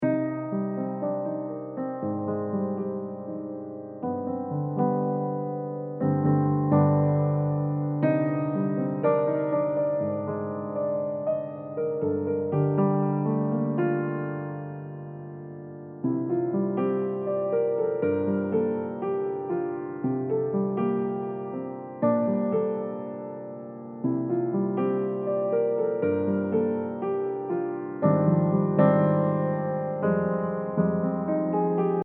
This is a short loop in the classic JRPG style.